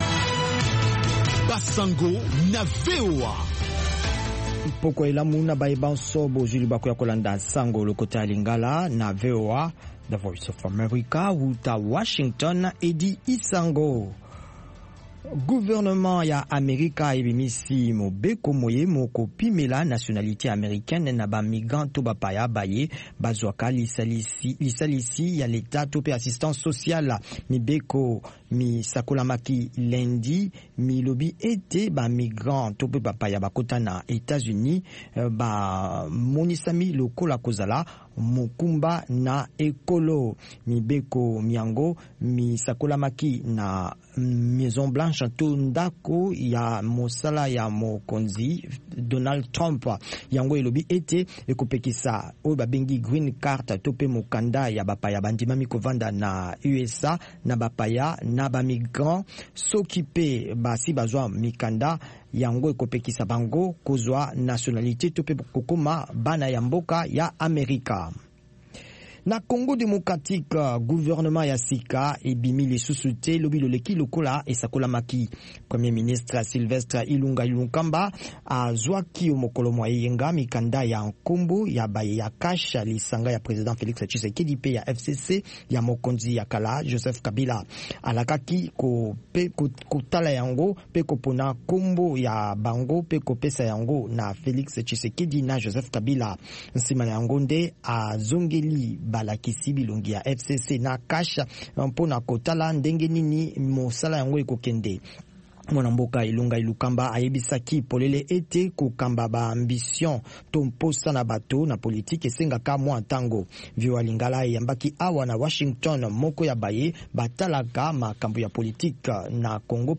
Radio
Basango na VOA Lingala